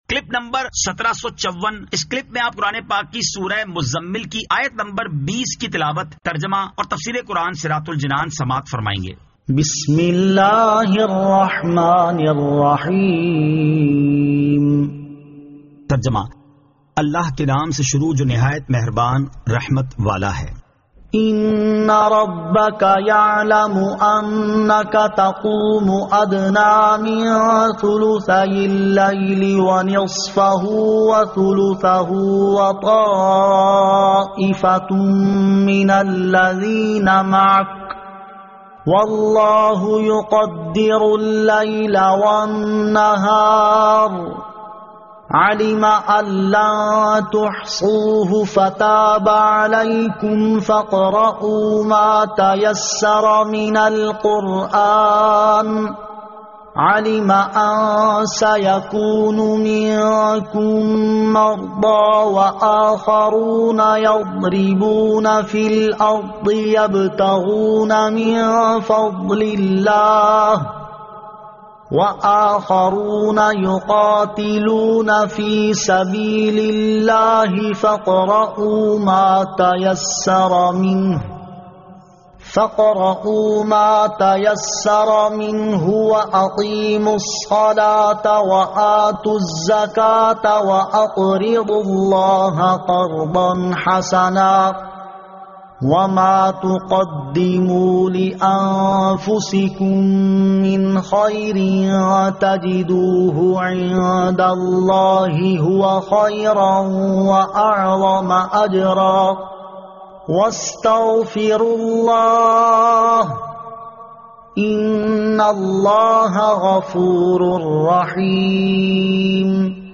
Surah Al-Muzzammil 20 To 20 Tilawat , Tarjama , Tafseer